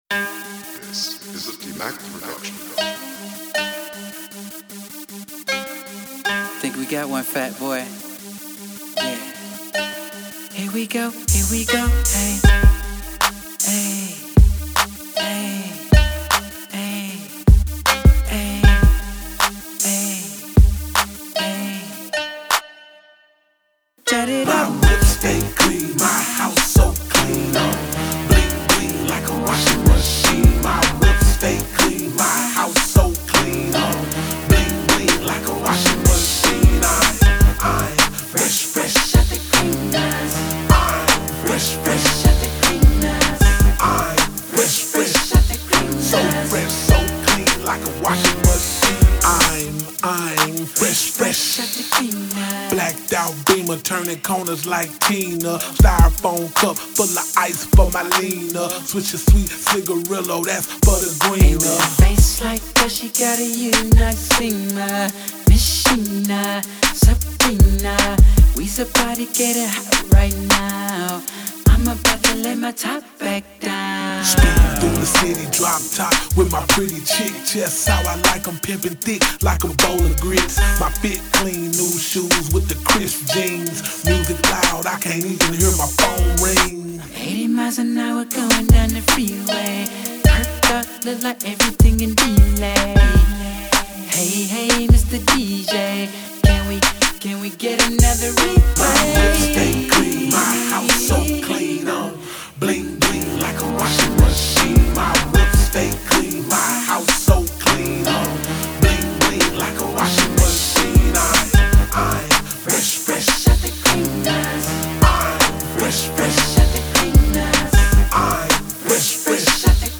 Genre: Memphis Rap.